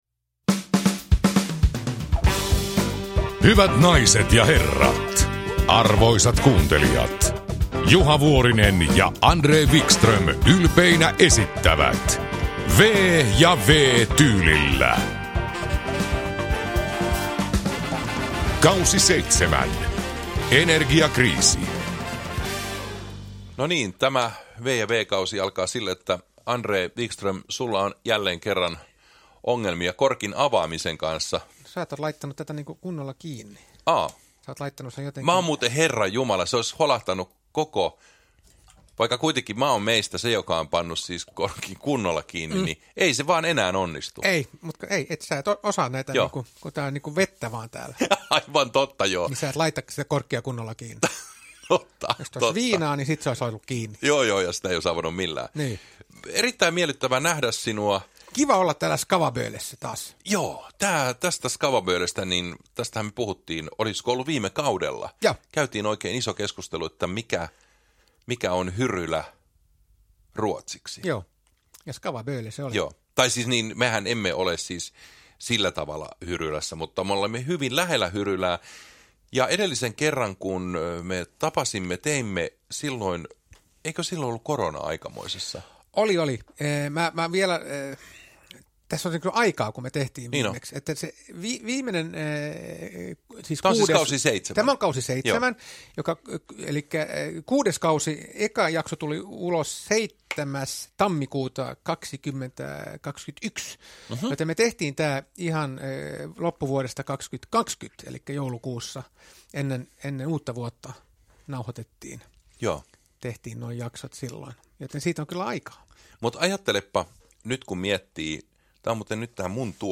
V- ja W-tyylillä K7 – Ljudbok
Uppläsare: Juha Vuorinen, André Wickström